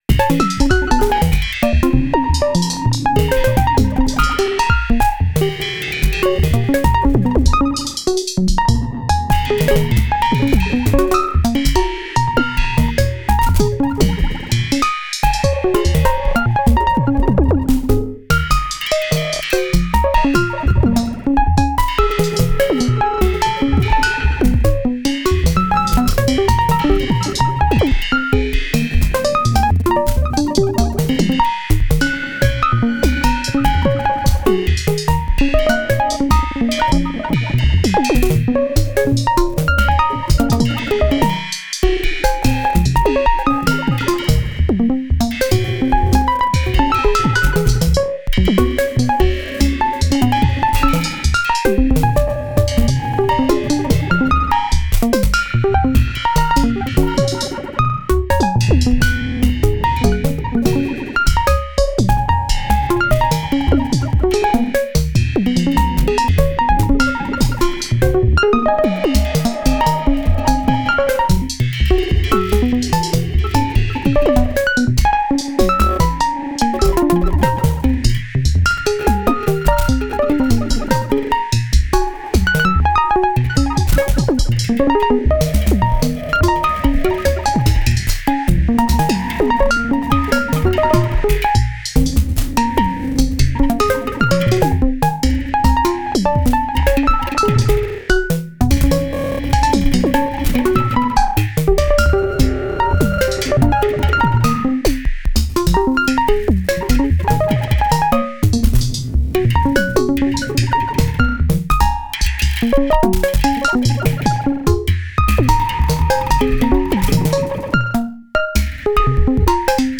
17th Jan 2022 | Experiment
Native Instruments REAKTOR Synthesizer / Software
Nord nord drum 3P Synthesizer / Analog Modeling
Voicing Ants Native Instruments Spriral Modified music sequencer